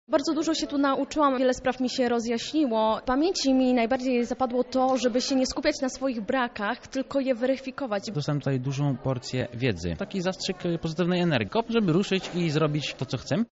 Nasza reporterka zapytała uczestników w czym pomogło im to szkolenie.